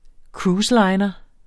Udtale [ ˈkɹuːsˌlɑjnʌ ]